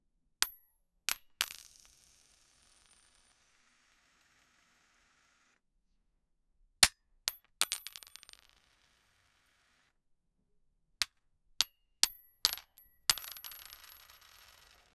Ammunition Sound Effects - Free AI Generator & Downloads
sound-of-loading-bullets--lovmjiec.wav